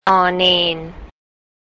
Pronunciation
นอ-เนน
nor nean